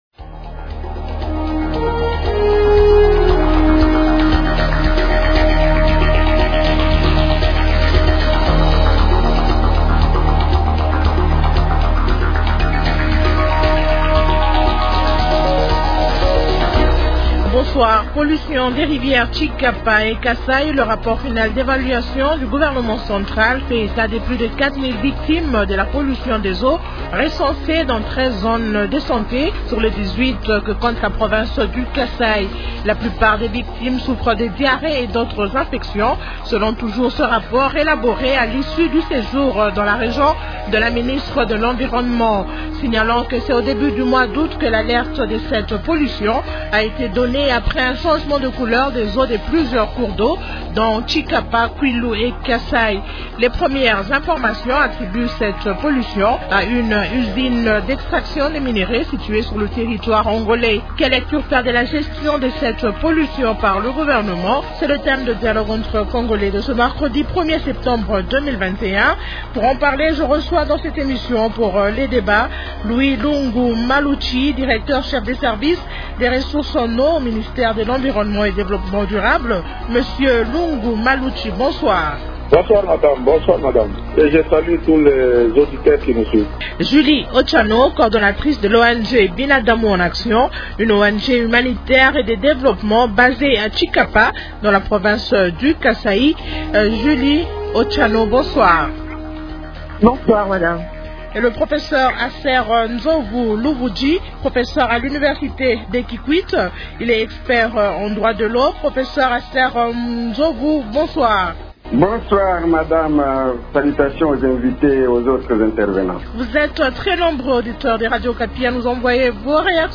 L'actualité politique de ce soir